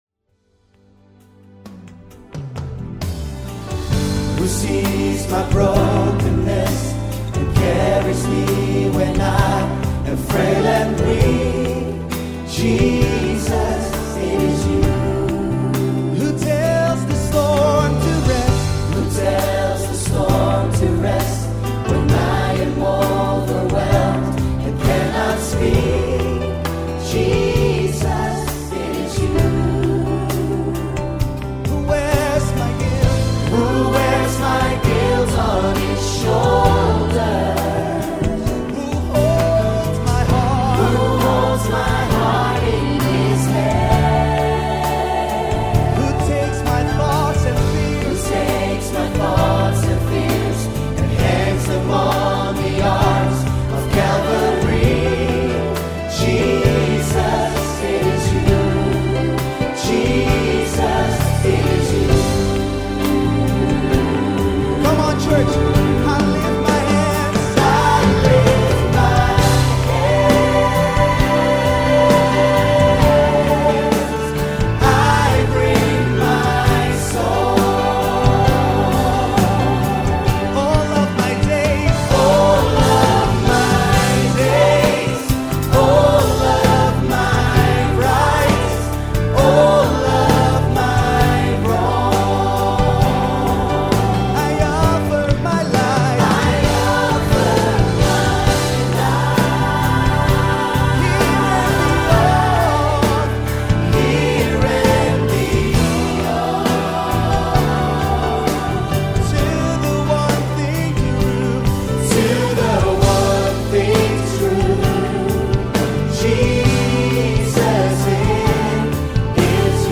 Genre Live Praise & Worship